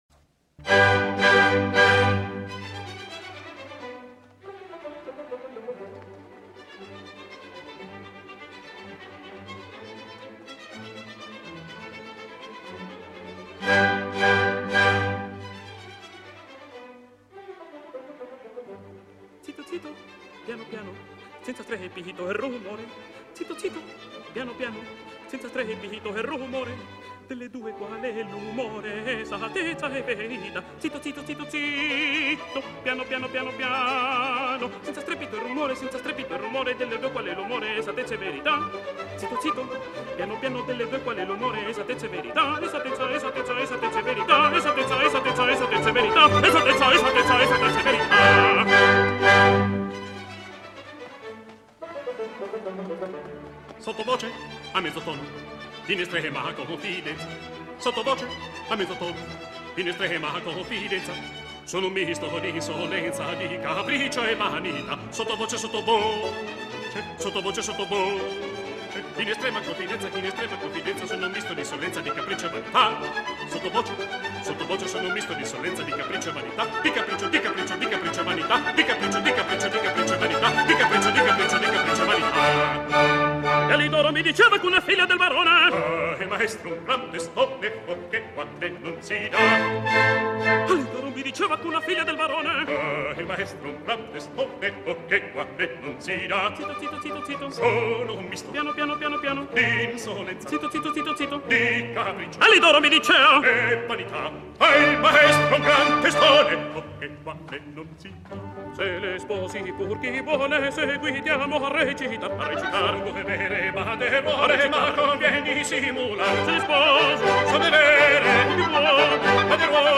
“Zitto Zitto, Piano Piano,” from La Cenerentola (1817). Music by Gioachino Rossini, libretto by Jacopo Ferretti. Performed by Francisco Araiza and Claudio Desderi.